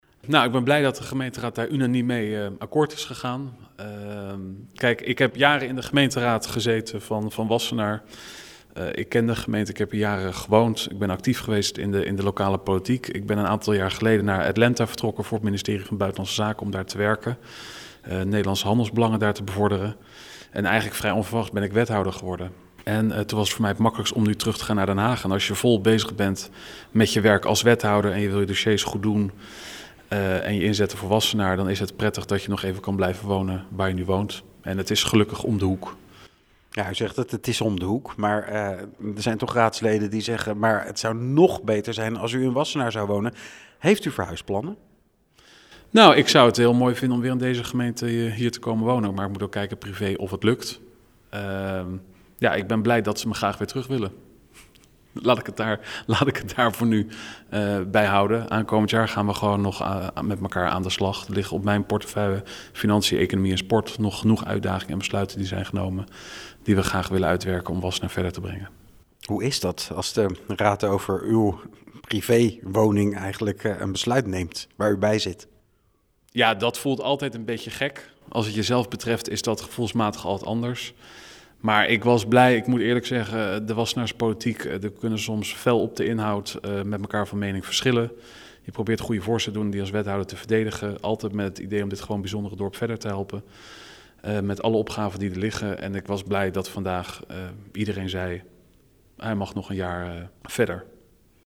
in gesprek met wethouder Laurens van Doeveren over de toestemming om in Den Haag te blijven wonen.